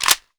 ak74_magin.wav